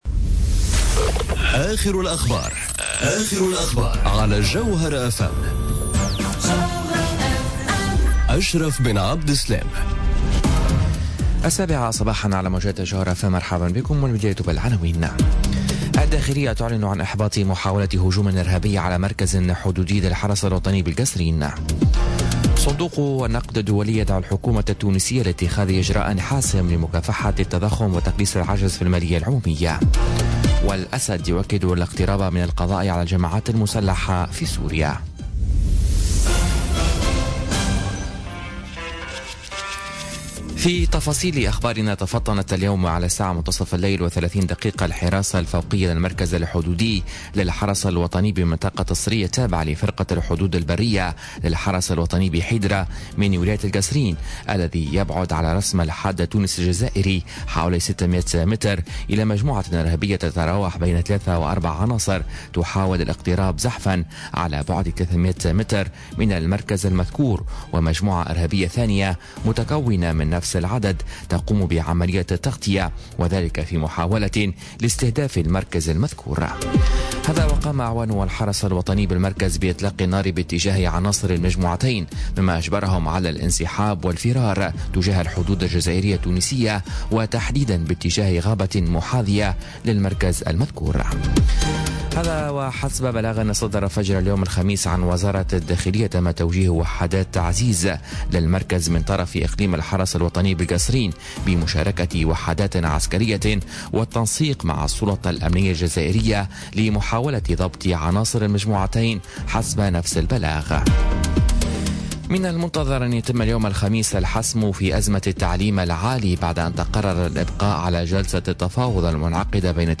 نشرة أخبار السابعة صباحا ليوم الخميس 31 ماي 2018